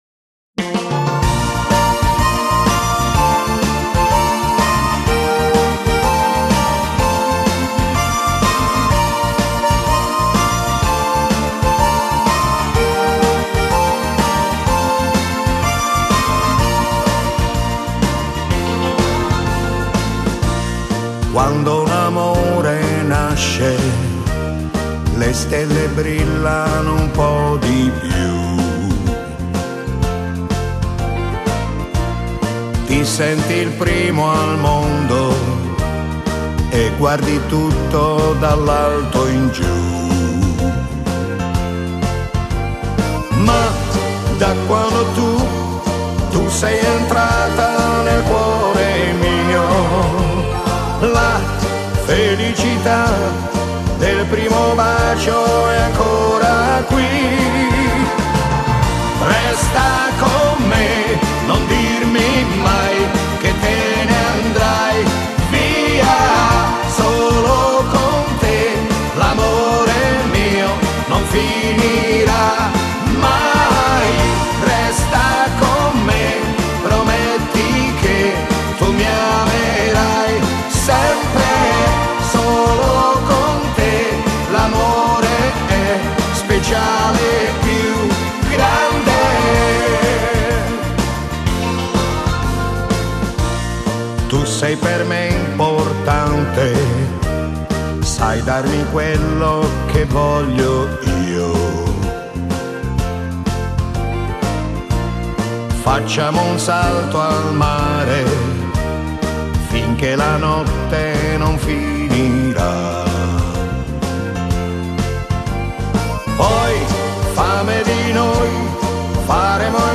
Genere: Moderato ballata